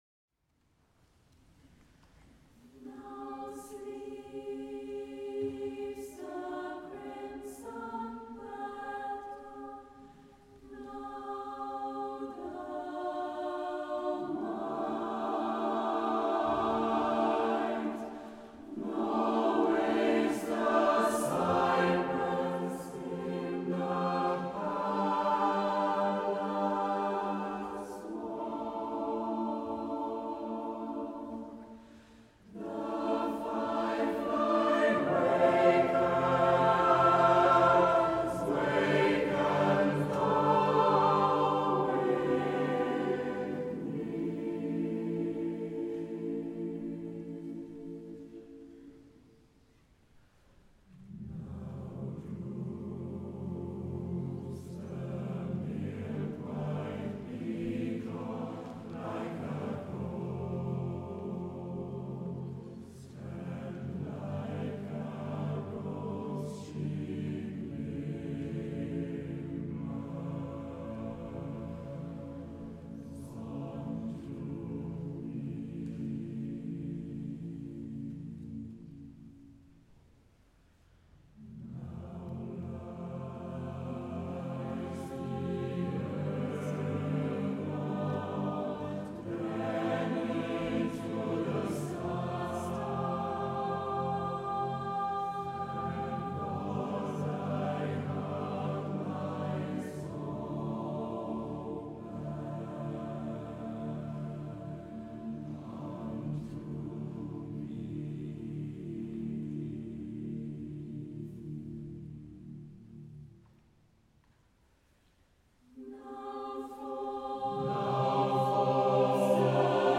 CD 7 - Live in Concert 2023
Tëuta su ai 05.11.2023 tla dlieja de Urtijëi